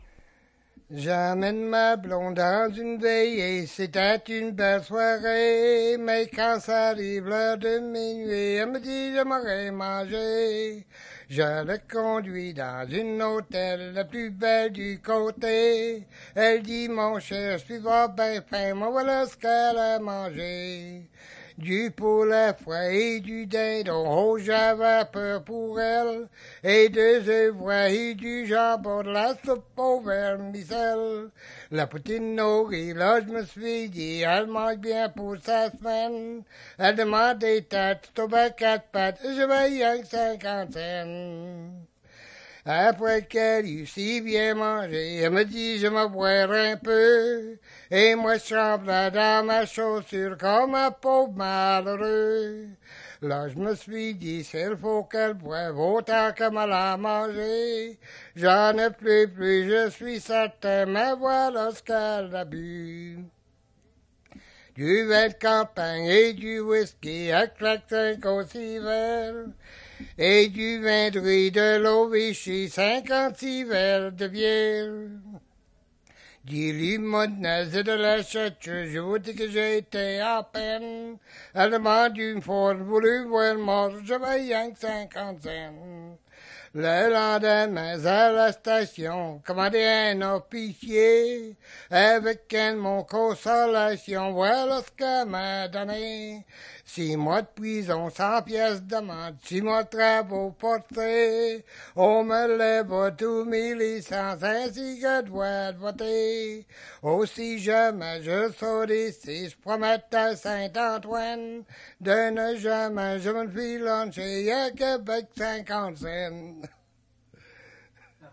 Chanson Item Type Metadata
Année de publication 1982 No d'acquisition 82-248 No de contrôle C5867 ID kg-46 Emplacement Lourdes-de-Blanc-Sablon Collecteur Kenneth Goldstein Géolocalisation